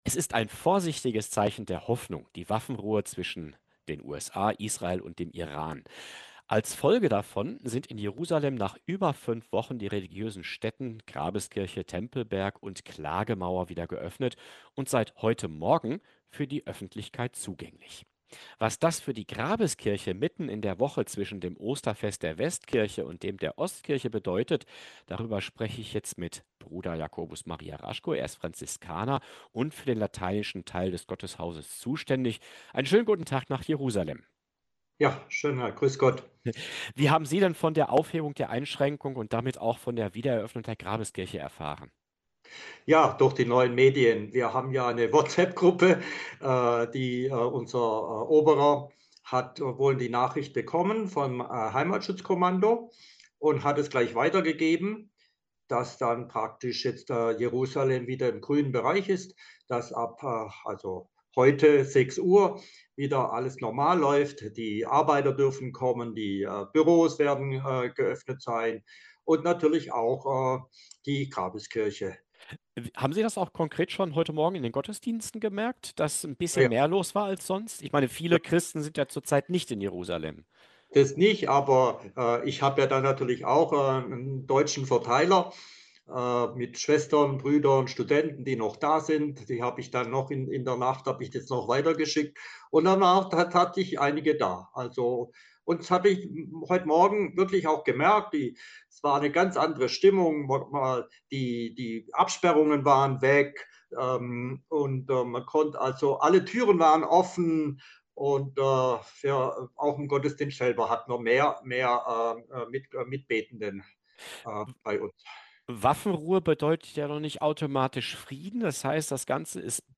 Franziskaner berichtet von besonderer Stimmung nach Öffnung der Grabeskirche